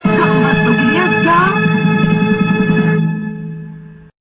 そして声でも玉緒さんがゲームを盛り上げます。
青い文字、またはスピーカーアイコンをクリックするとRealAudioで玉緒さんの声を聞くことができます。